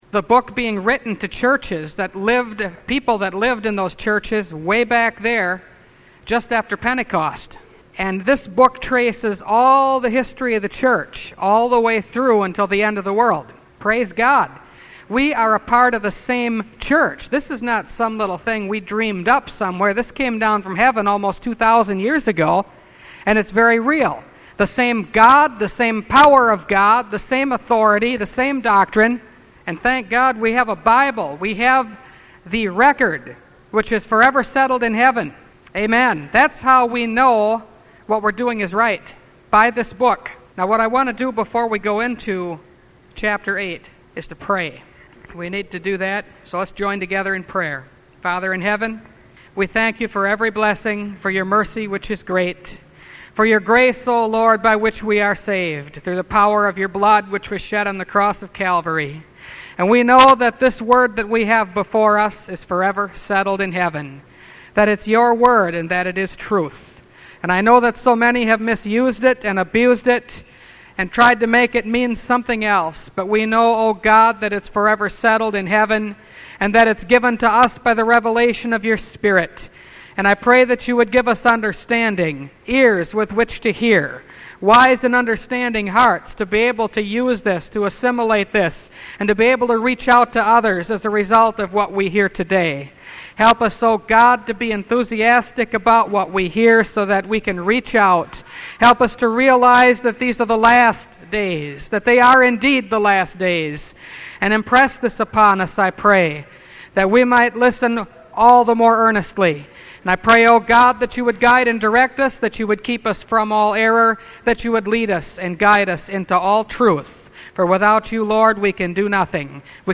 Revelation Series – Part 11 – Last Trumpet Ministries – Truth Tabernacle – Sermon Library